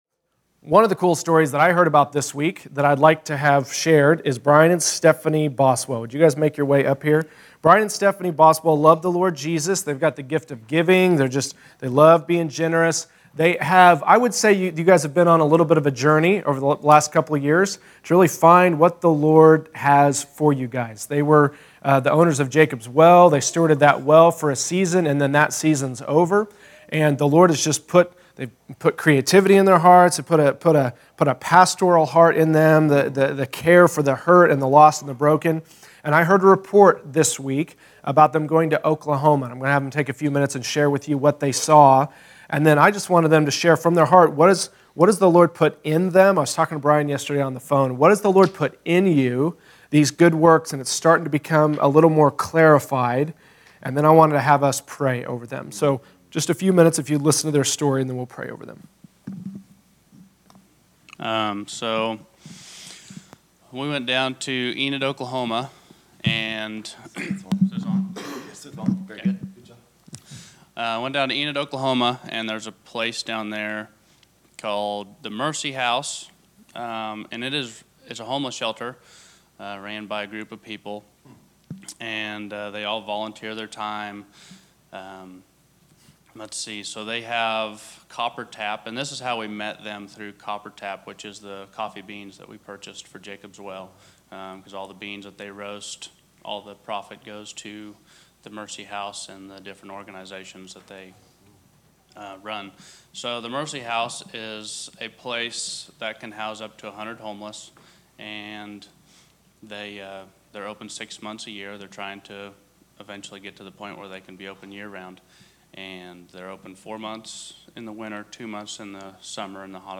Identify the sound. Category: Testimonies